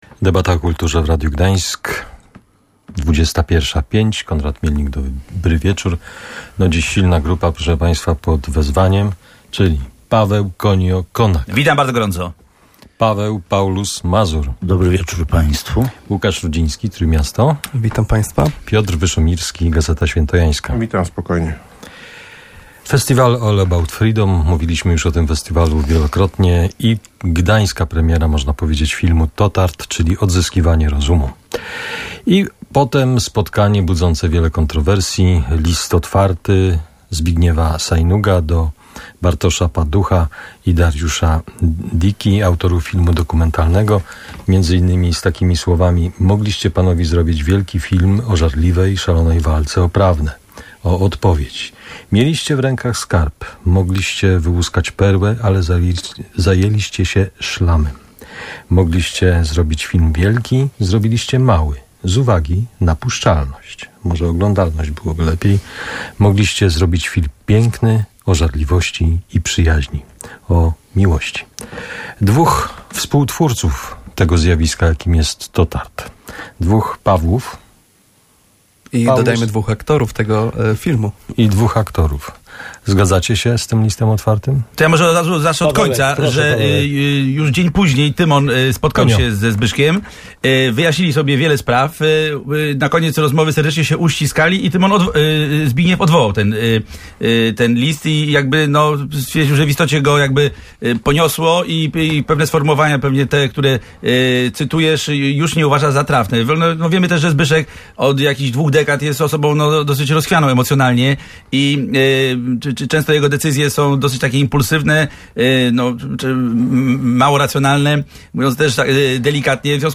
Totart czyli odzyskiwanie rozumu – dyskusja o filmie dokumentalnym